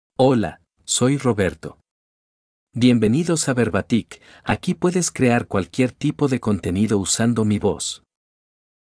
MaleSpanish (Panama)
Roberto — Male Spanish AI voice
Roberto is a male AI voice for Spanish (Panama).
Voice sample
Listen to Roberto's male Spanish voice.
Roberto delivers clear pronunciation with authentic Panama Spanish intonation, making your content sound professionally produced.